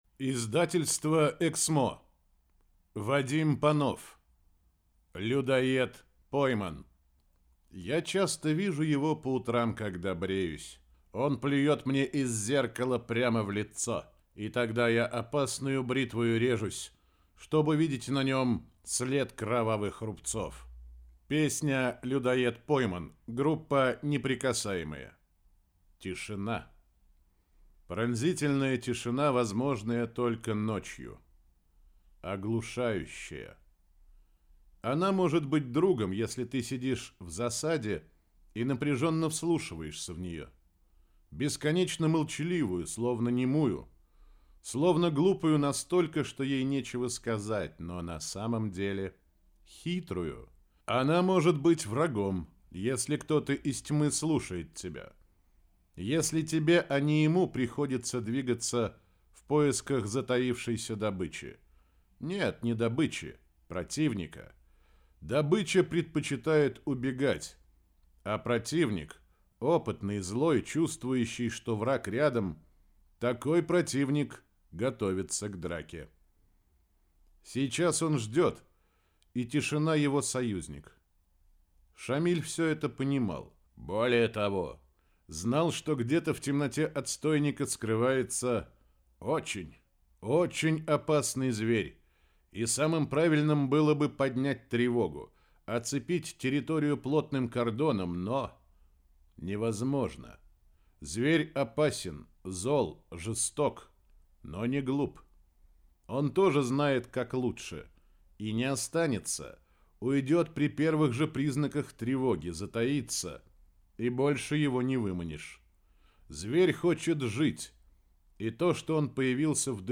Аудиокнига Людоед пойман | Библиотека аудиокниг